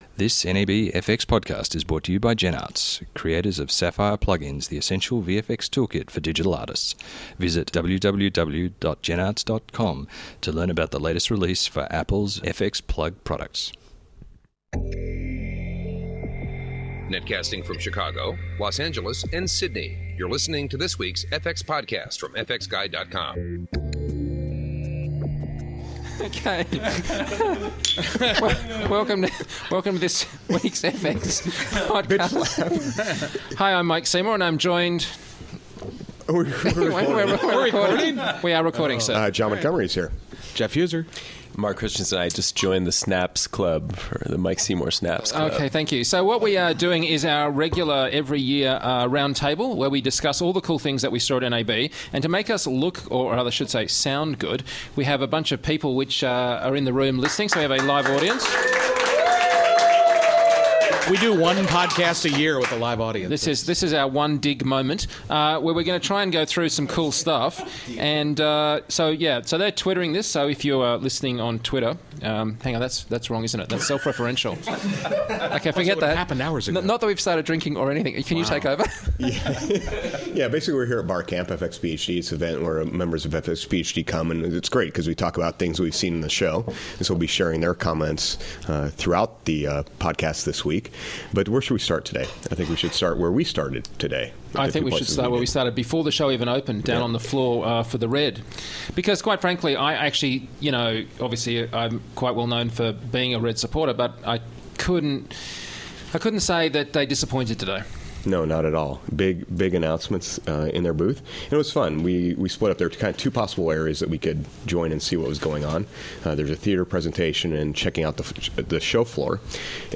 Our annual roundtable from fxphd barcamp. We cover the announcements from RED, Eyeon, and more.